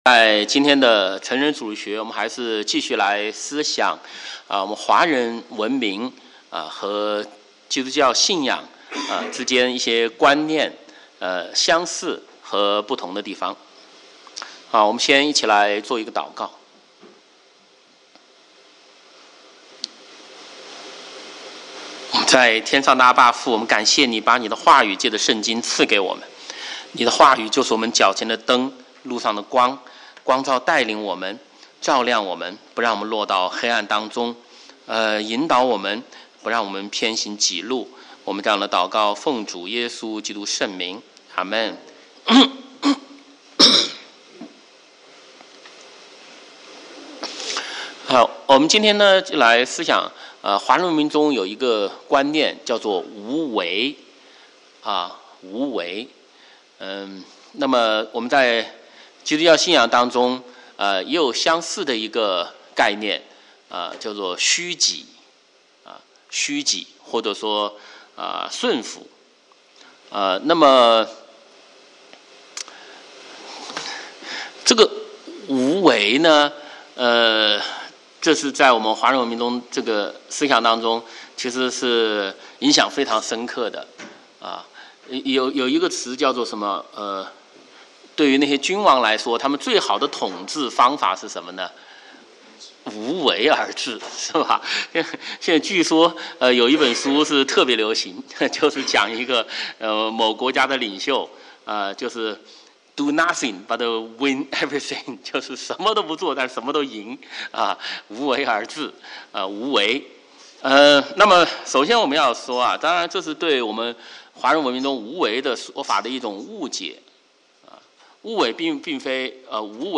主日學